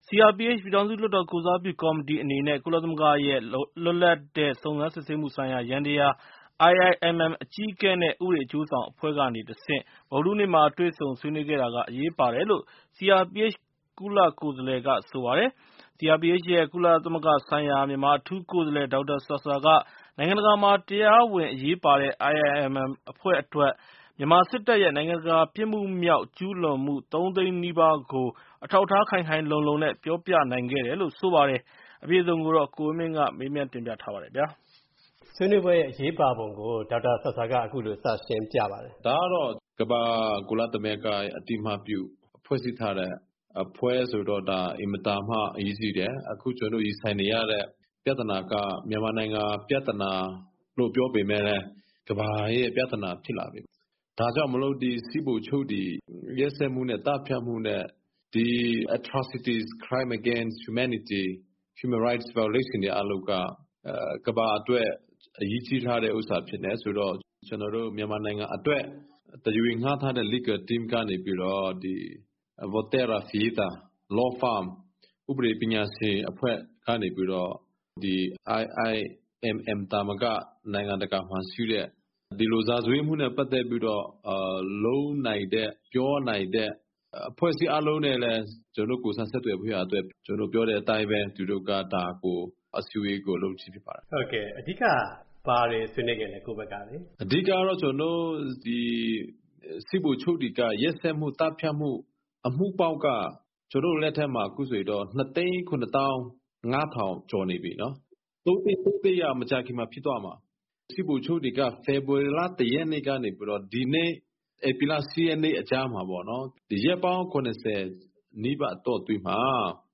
IIMM နဲ့ဆွေးနွေးမှုပေါ် ဒေါက်တာဆာဆာနဲ့ ဆက်သွယ်မေးမြန်းချက်